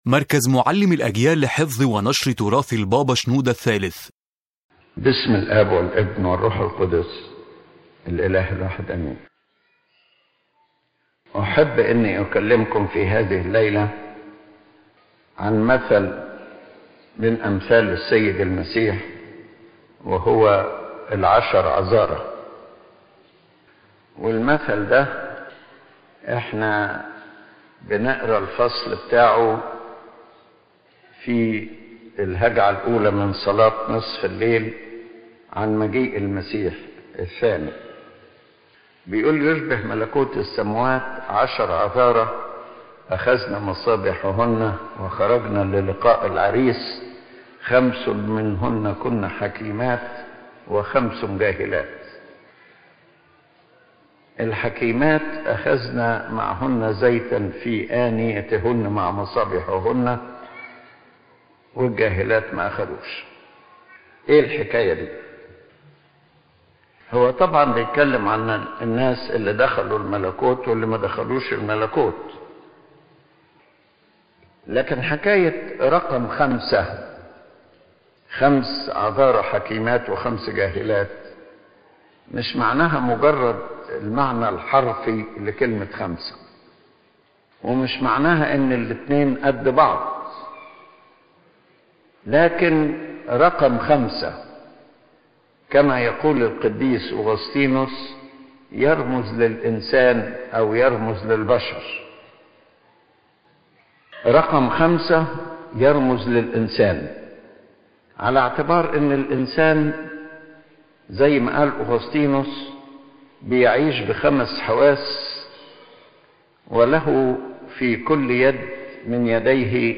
Lecture Summary – General Spiritual Message